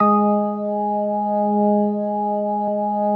B3LESLIE G#4.wav